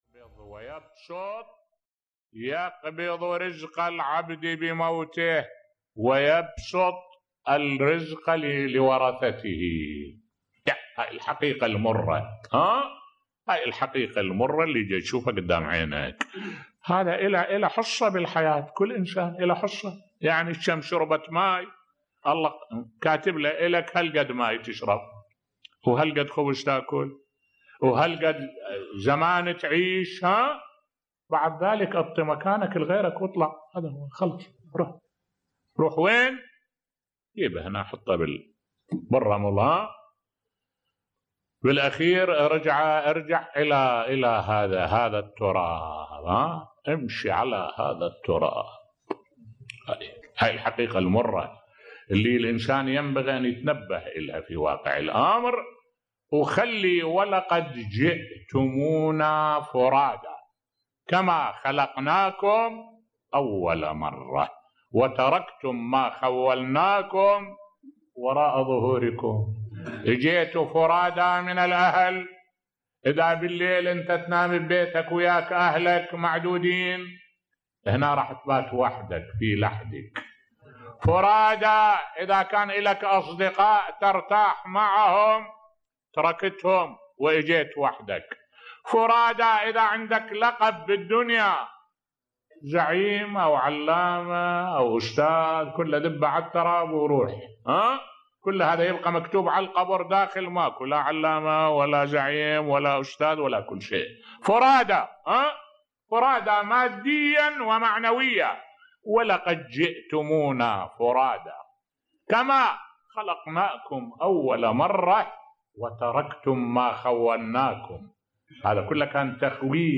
ملف صوتی أصيل الموت، الحقيقة المرة بصوت الشيخ الدكتور أحمد الوائلي